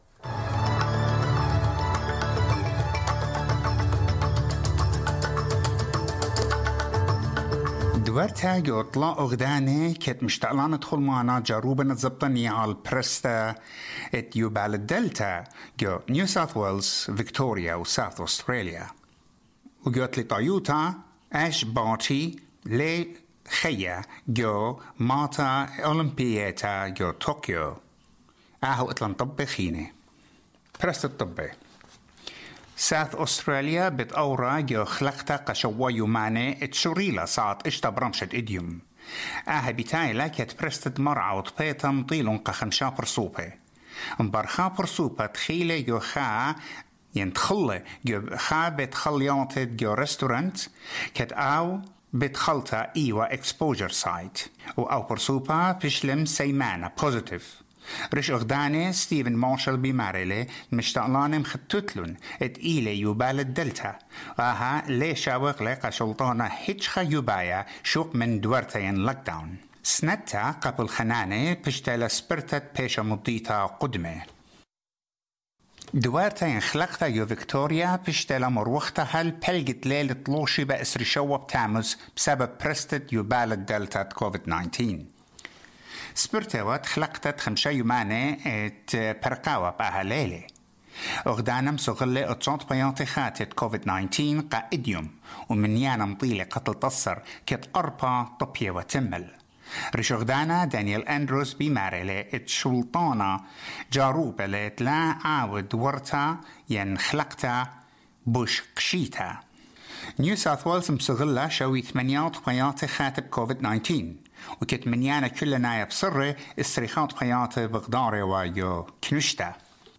Latest national and international news